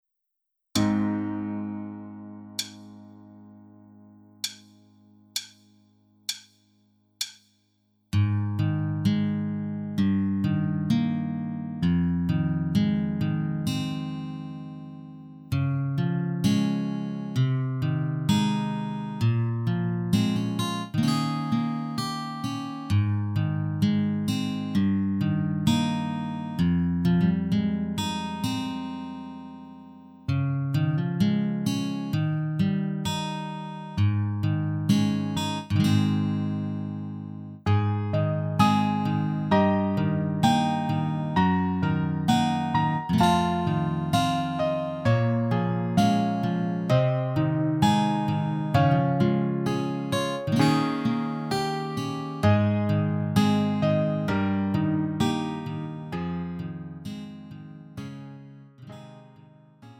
음정 원키 3:59
장르 구분 Lite MR